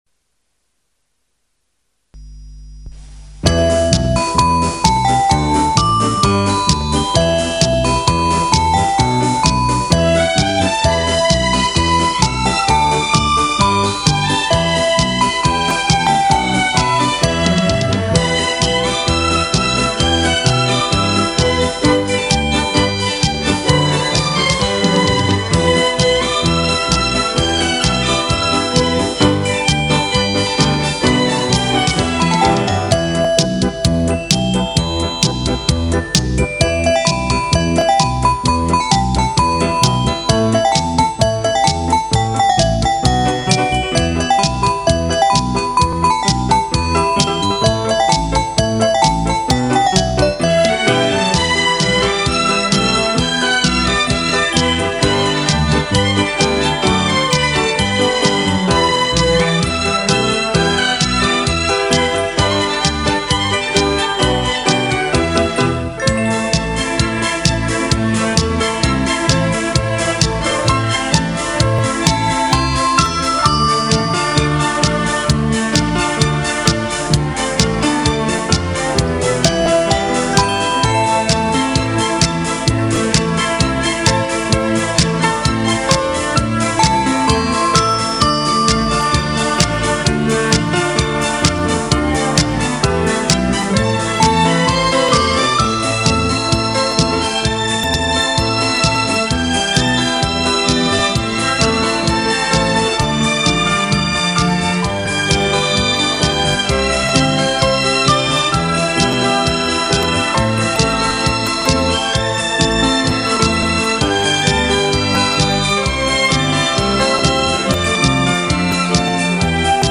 [交谊舞曲 北京平四 草原牧歌 音乐] 激动社区，陪你一起慢慢变老！